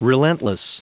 Prononciation du mot relentless en anglais (fichier audio)
Prononciation du mot : relentless